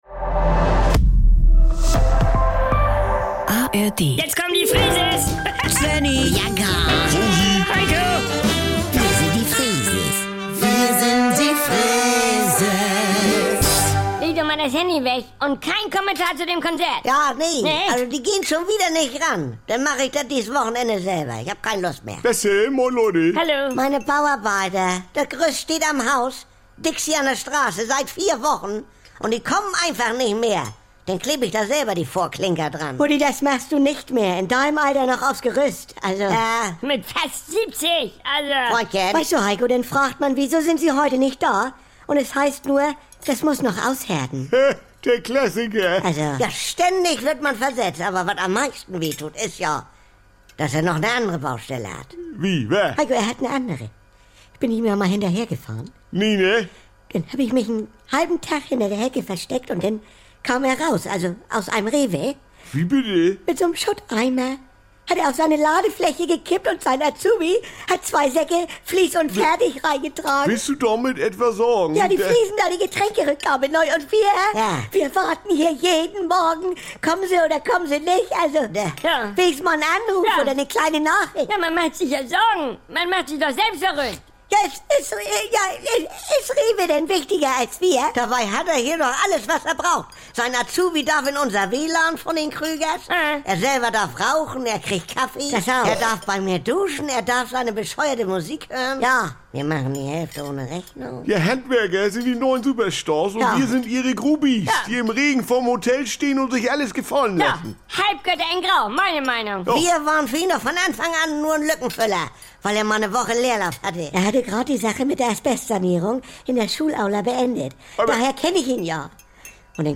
Saubere Komödien NDR 2 Komödie Unterhaltung NDR Freeses Comedy